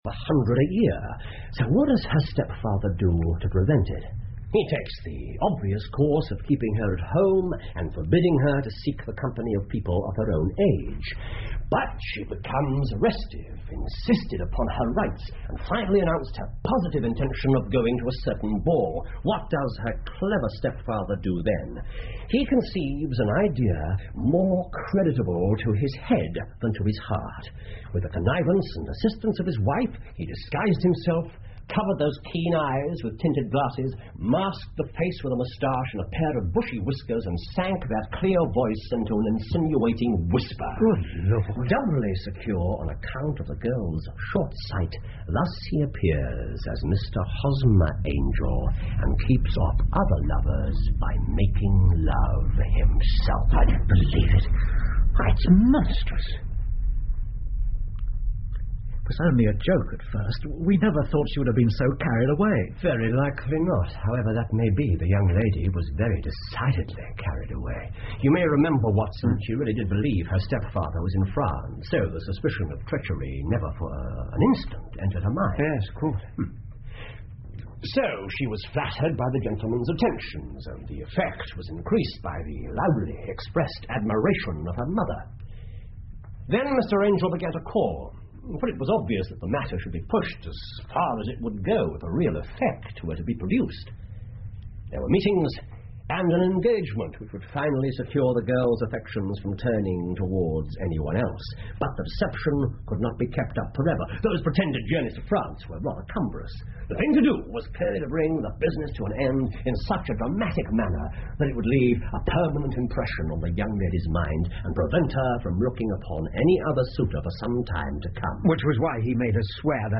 福尔摩斯广播剧 A Case Of Identity 8 听力文件下载—在线英语听力室
在线英语听力室福尔摩斯广播剧 A Case Of Identity 8的听力文件下载,英语有声读物,英文广播剧-在线英语听力室